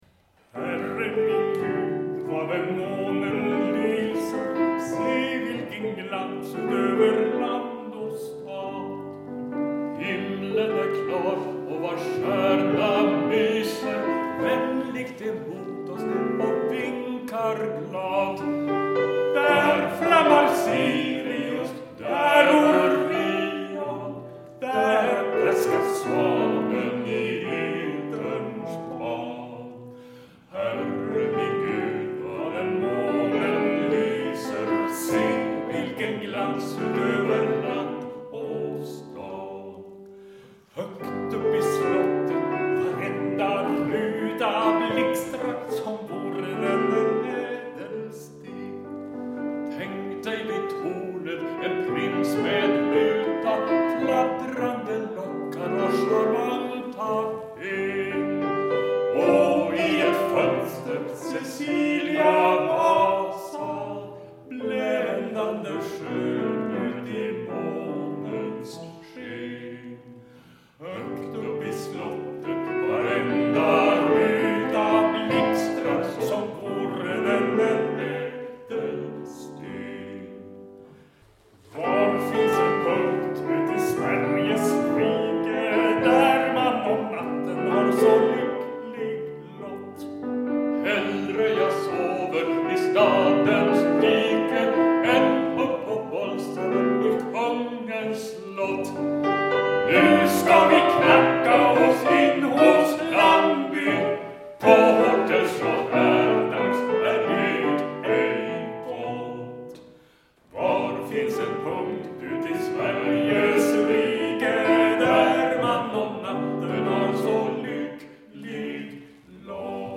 Dessa två amatörinspelningar finns inte till salu så det är helt OK att kopiera rubriker till en eller flera gluntar eller till hela skivor och sända till vänner – och varför inte till fiender?
accompagnement på piano
Flottsundkyrkan 6 december 2014